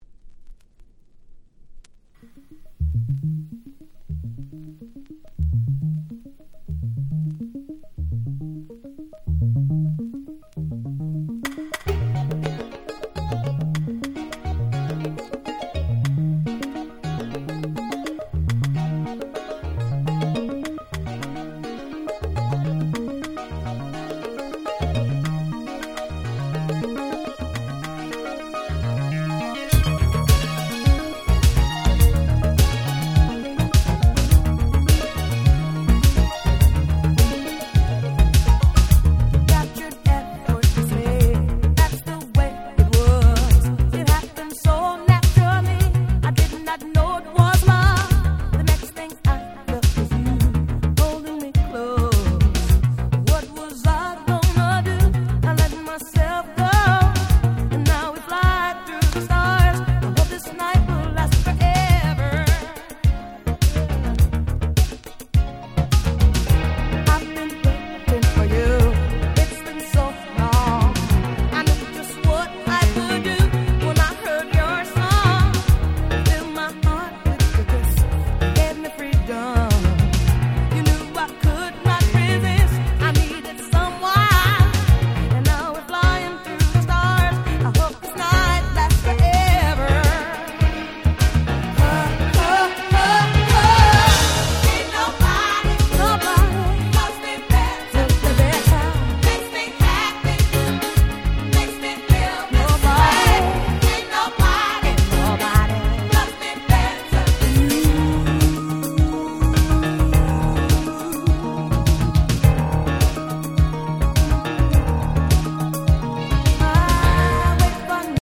89' Nice Remix !!
原曲に忠実ながらもよりフロアを意識した89年っぽいNiceRemixです！！
80's Disco ディスコ ダンクラ ダンスクラシックス Dance Classics